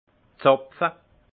Prononciation 68 Munster